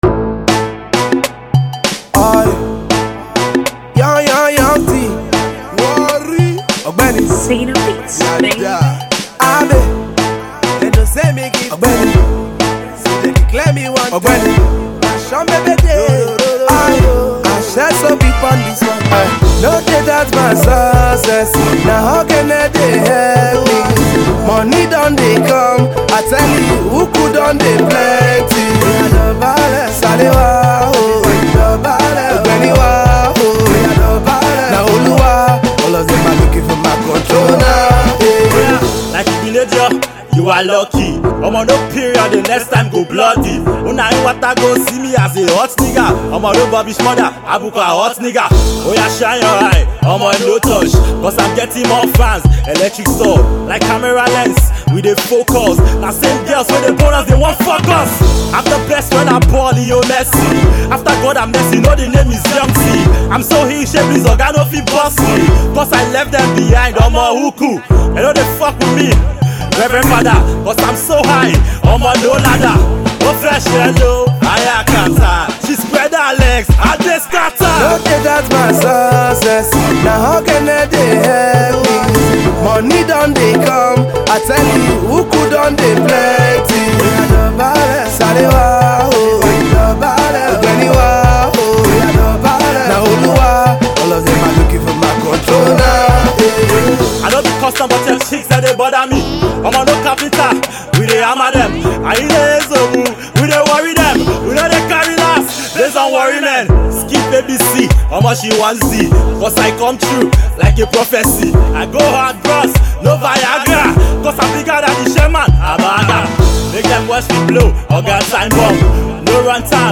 Rap song
its a hilarious Rap song